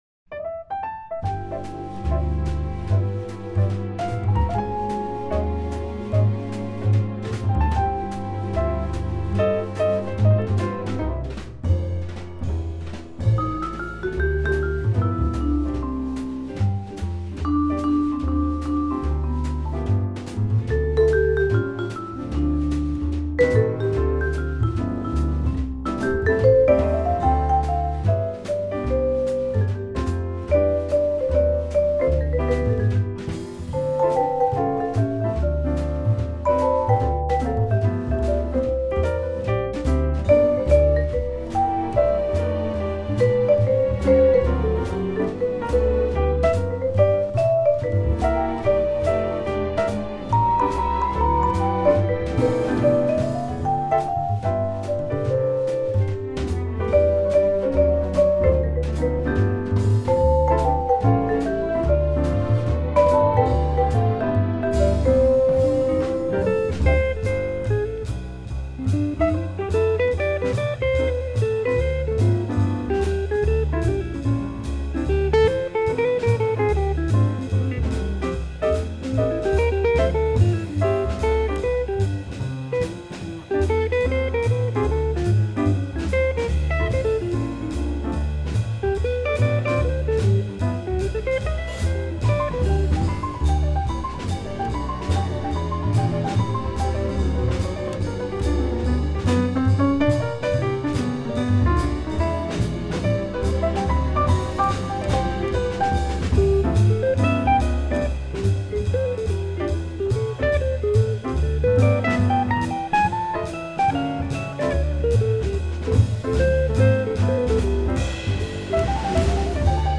jazz
Vibes
a tropical feel